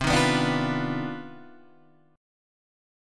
CmM11 chord